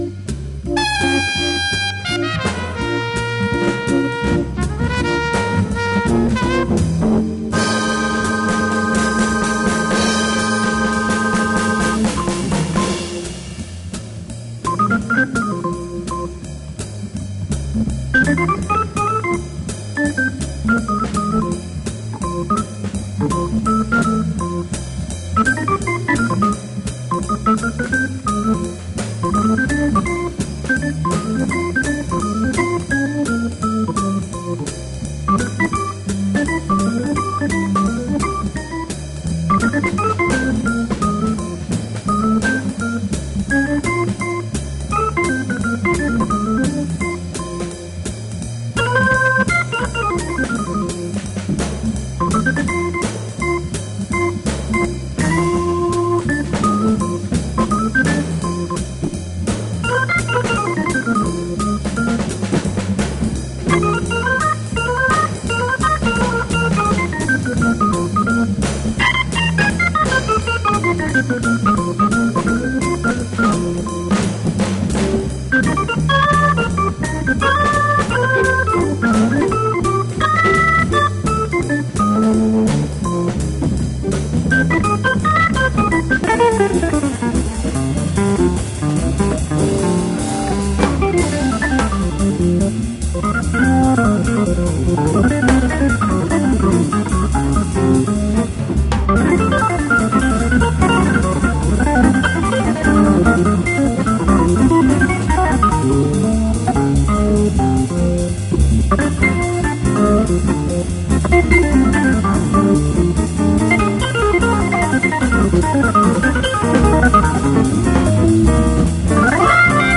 Ce sont des éditions récentes de concerts enregistrés.